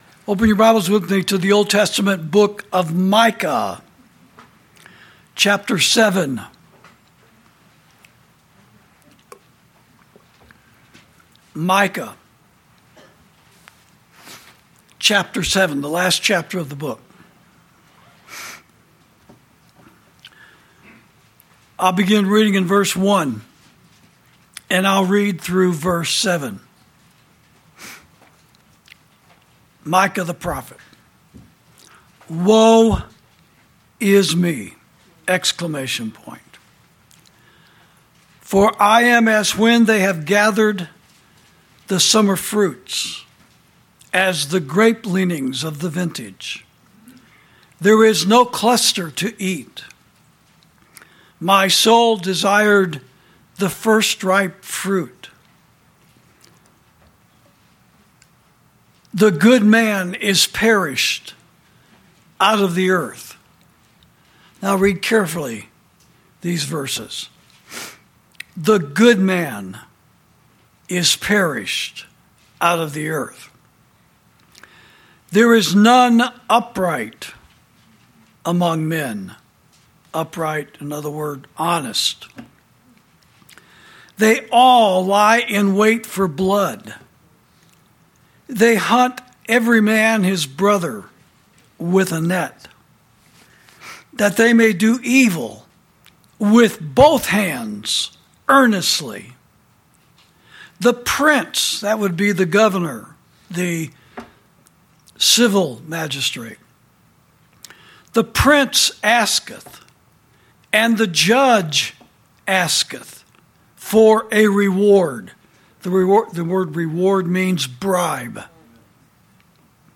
Sermons > Not Every "Christian" Is Our Friend; Not Every "Non-Christian" Is Our Enemy